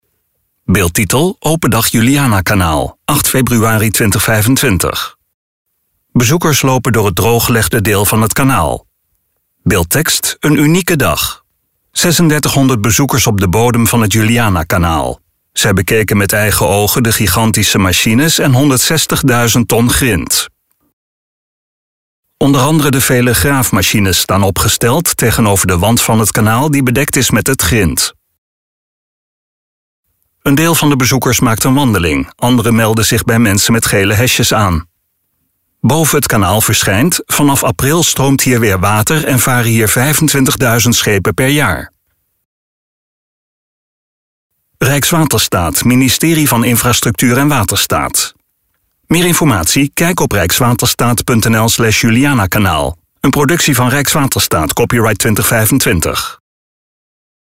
STEVIGE MUZIEK TOT HET EIND VAN DE VIDEO (Een deel van de bezoekers maakt een wandeling, andere melden zich bij mensen met gele hesjes aan.